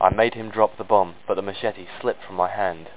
home *** CD-ROM | disk | FTP | other *** search / Horror Sensation / HORROR.iso / sounds / iff / madehimd.snd ( .mp3 ) < prev next > Amiga 8-bit Sampled Voice | 1992-09-02 | 27KB | 1 channel | 9,016 sample rate | 3 seconds